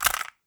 CASSETTE_RATTLE_12.wav